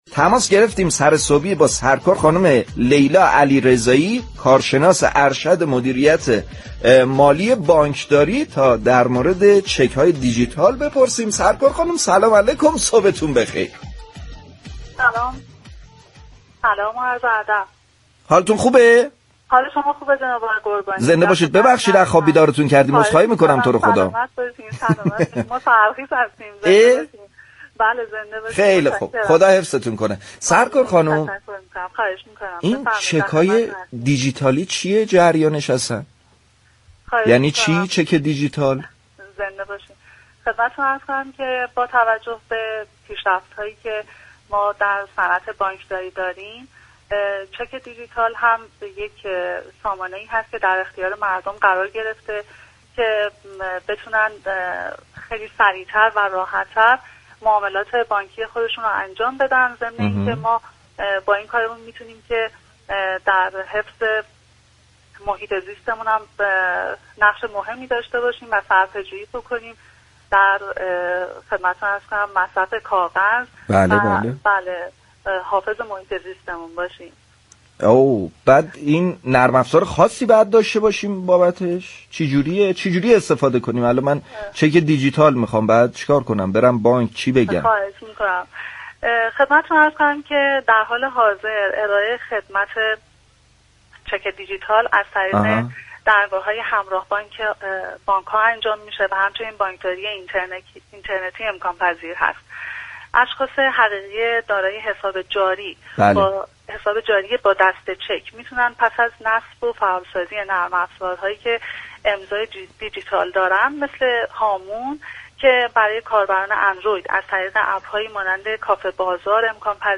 گفت و‌ گو